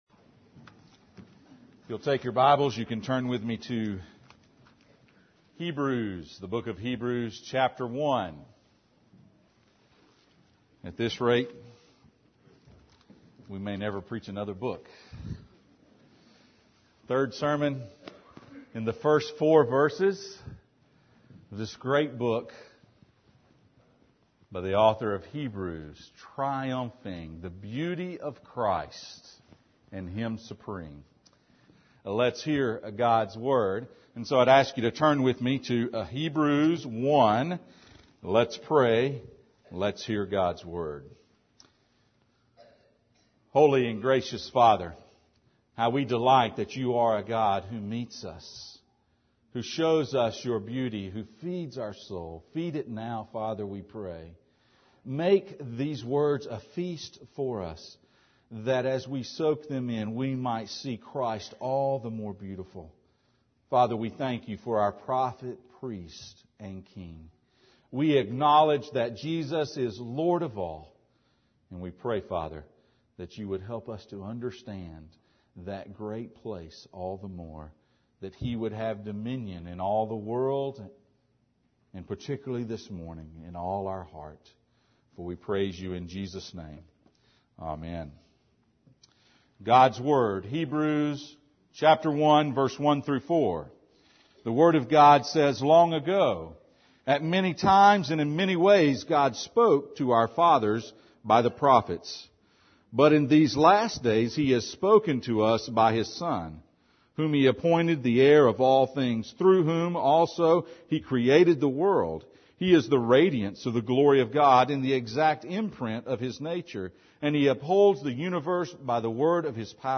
Passage: Hebrews 1:1-4 Service Type: Sunday Morning